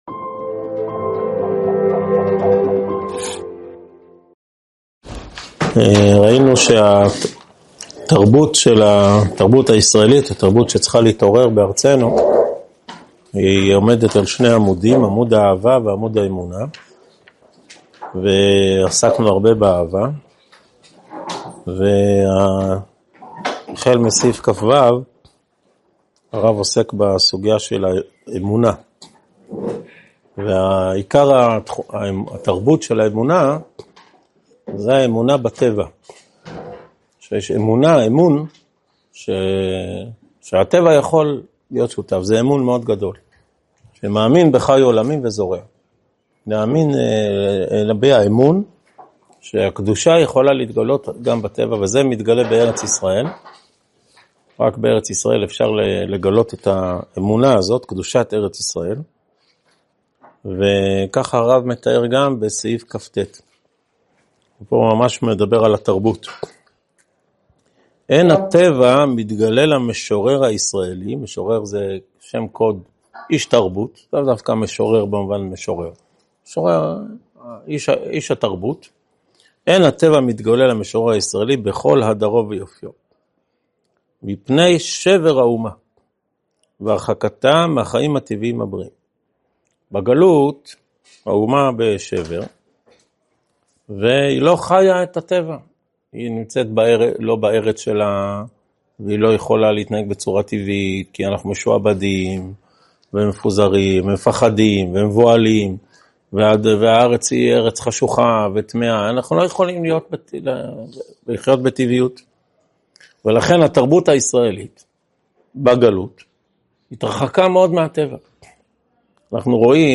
שיעור 35 מתוך 59 בסדרת אורות התחיה
הועבר בישיבת אלון מורה בשנת תשפ"ה.